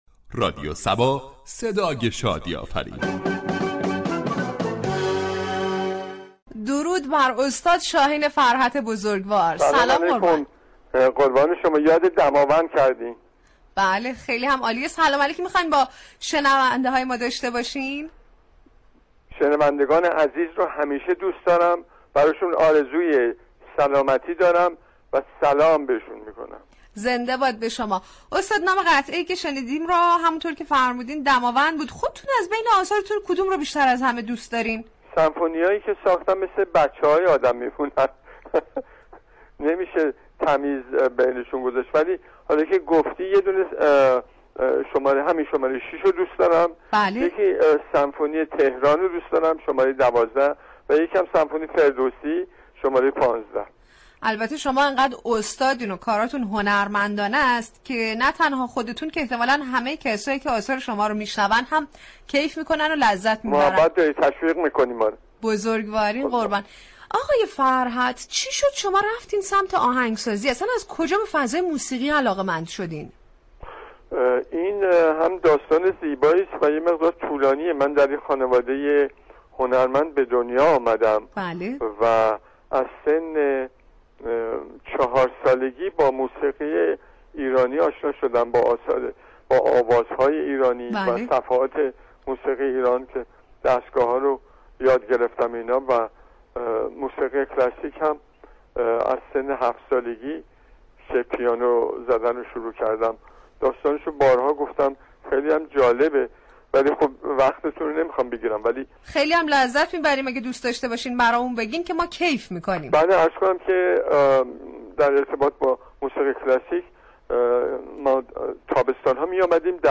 گفتگوی صباهنگ با
گفتگوی تلفنی صباهنگ با